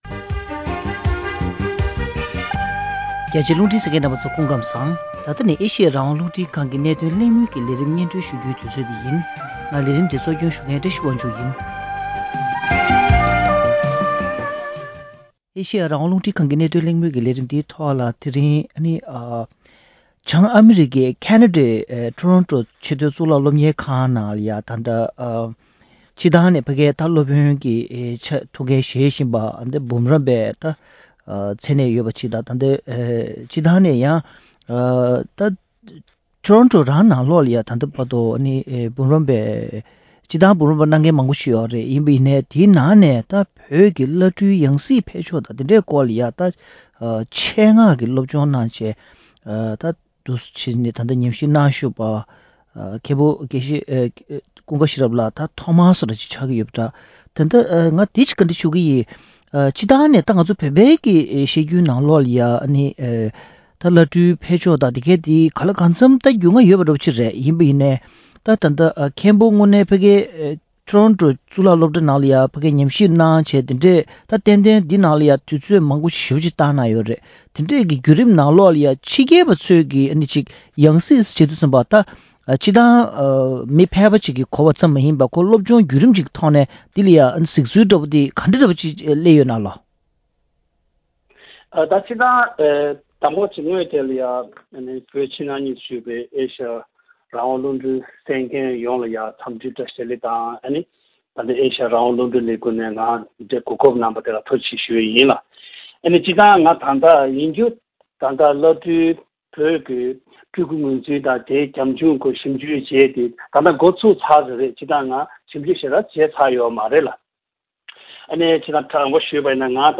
བོད་ཀྱི་བླ་སྤྲུལ་ཡང་སྲིད་ངོས་འཛིན་ཞུ་ཕྱོགས་ལ་ནུབ་ཕྱོགས་ཉམས་ཞིབ་པའི་ལྟ་ཚུལ་དང་བོད་རྒྱུད་ནང་བསྟན་གྱི་བླ་སྤྲུལ་ཡང་སྲིད་ཀྱི་ཕན་ནུས་དང་ཤུགས་རྐྱེན་ཐད་གླེང་མོལ།